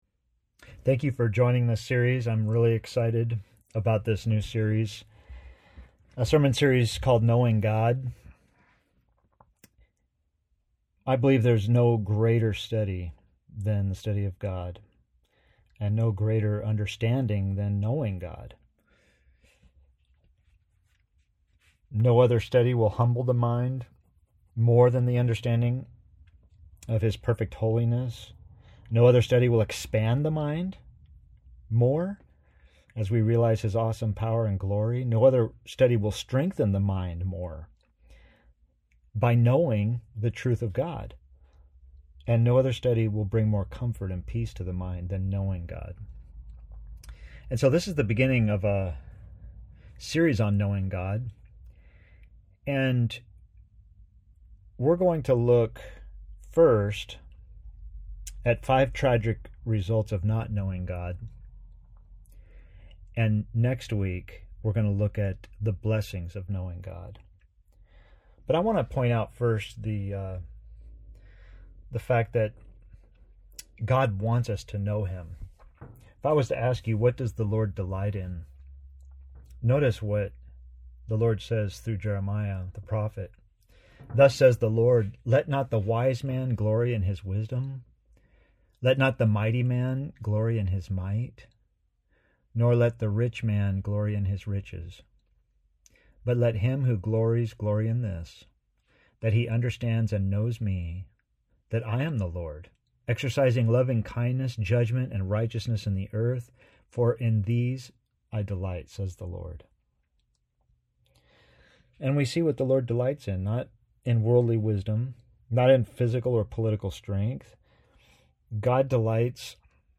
In this first sermon in a series, let’s recognize Five Tragic Results of Not Knowing God.